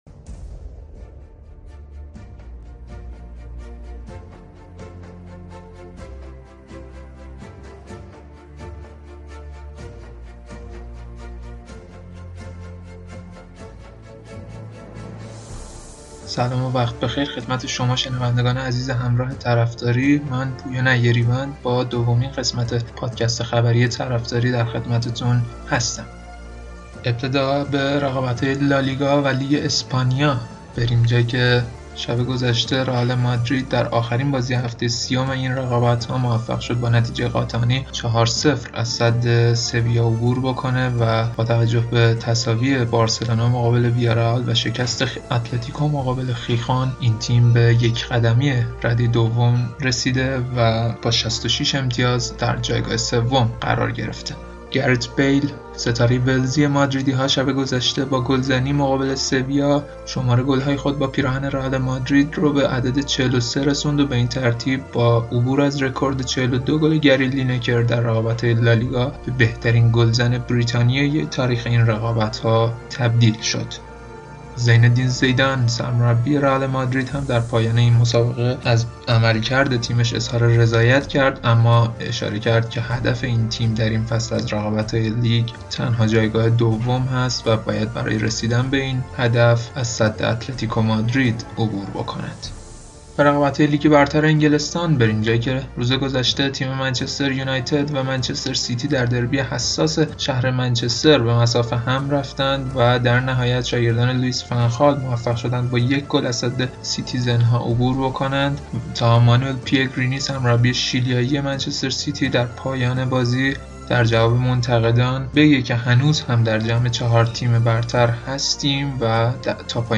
چقدر صداش میلرزه ، نترس جوان :)